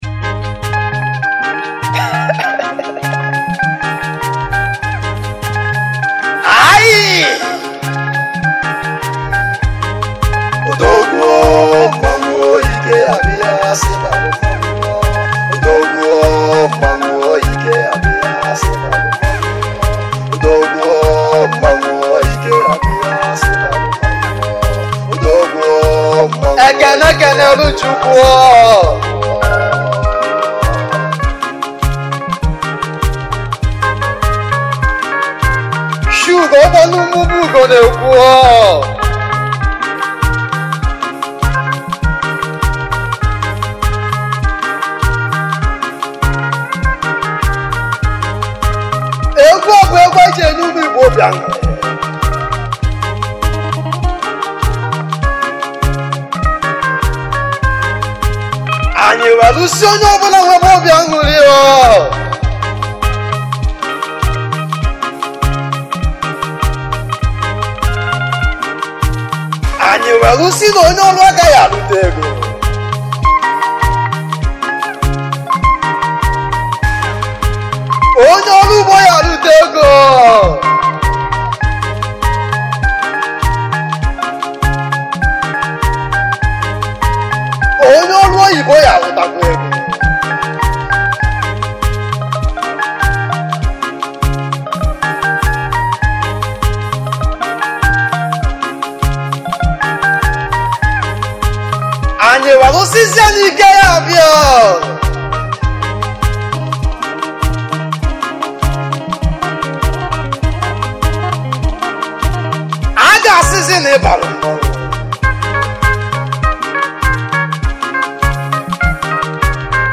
igbo highlife musician's
highlife music band